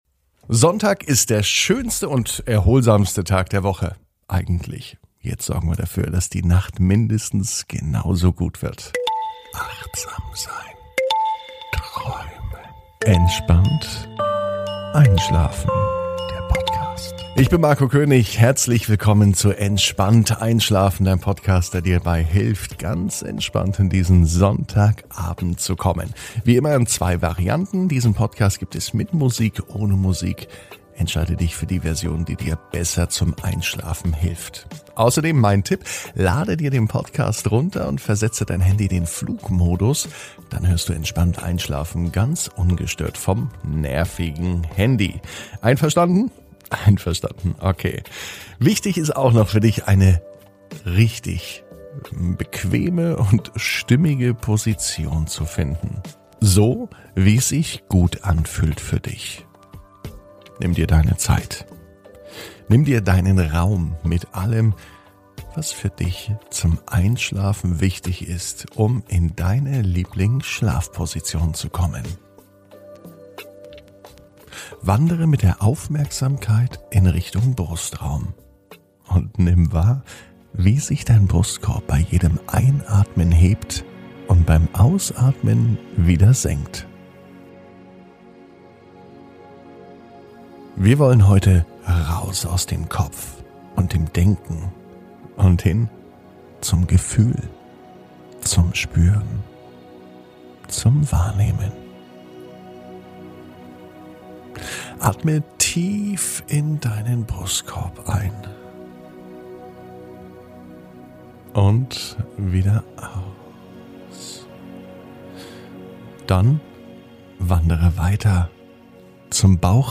(ohne Musik) Entspannt einschlafen am Sonntag, 30.05.21 ~ Entspannt einschlafen - Meditation & Achtsamkeit für die Nacht Podcast